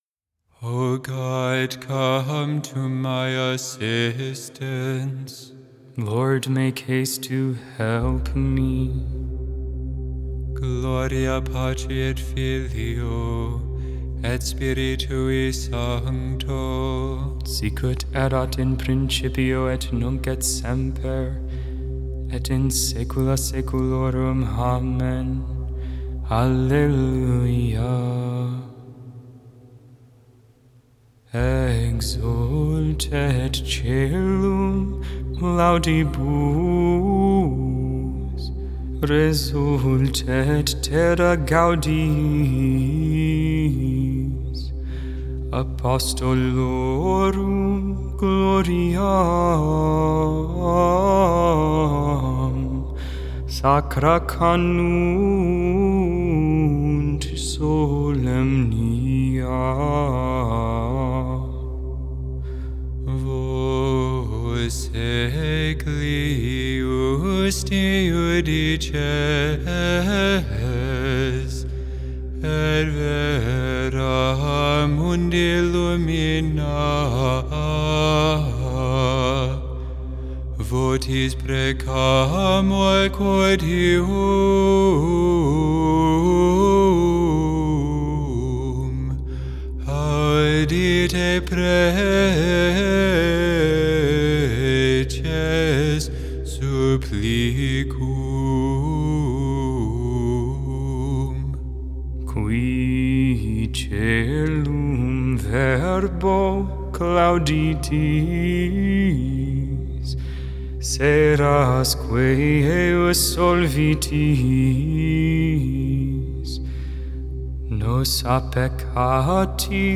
Vespers, Evening Prayer on the 30th Friday in Ordinary Time, October 28th, 2022.